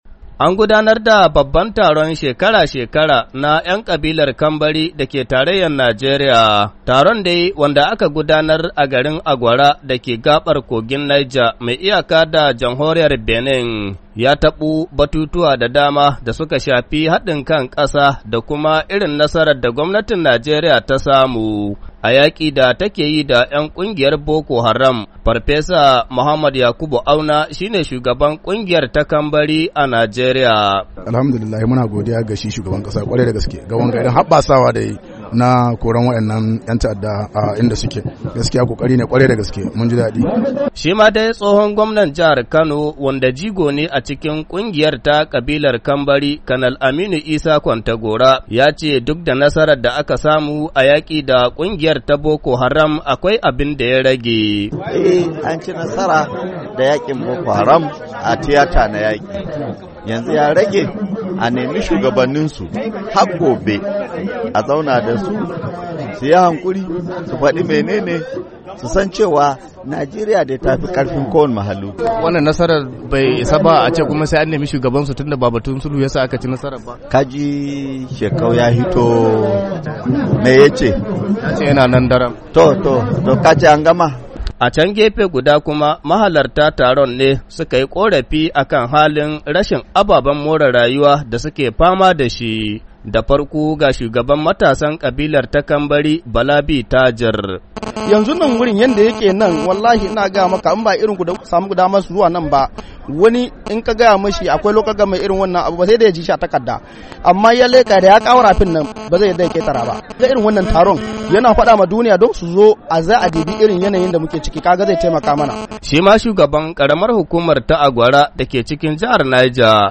Kowace shekara kabilar Kambari ke gudanar da babban taronsu kamar yadda suka yi a garin Agwara dake jihar Neja